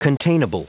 Prononciation du mot containable en anglais (fichier audio)